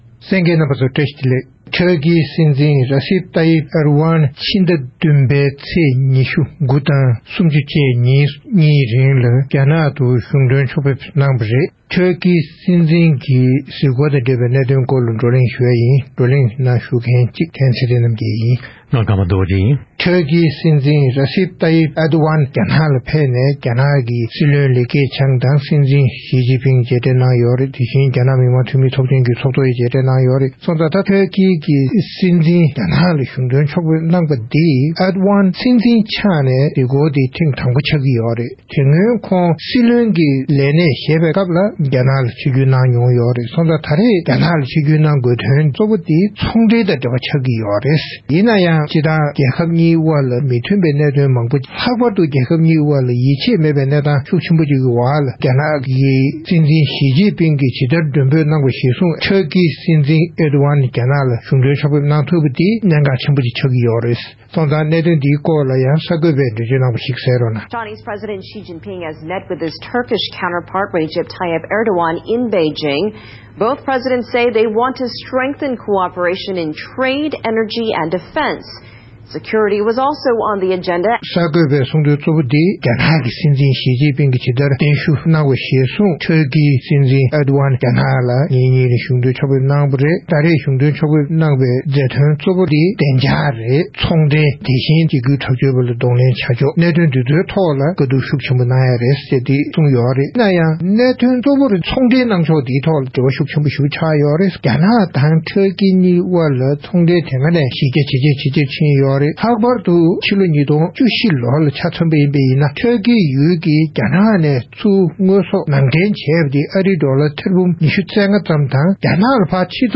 ༄༅། །རྩོམ་སྒྲིག་པའི་གླེང་སྟེགས་ཀྱི་ལེ་ཚན་ནང་། ཊར་ཀི་སྲིད་འཛིན་ Recep Tayyip Erdogan མཆོག་་ནས་ཕྱི་ཟླ་༧ཚེས་༢༩དང་༣༠བཅས་ཉིན་གྲངས་གཉིས་ཀྱི་རིང་རྒྱ་ནག་ཏུ་འཚམས་གཟིགས་གནང་ཡོད་པ་རེད། དེའི་སྐོར་གླེང་མོལ་ཞུས་པའི་ལས་རིམ་ཞིག་གསན་རོགས་གནང་།།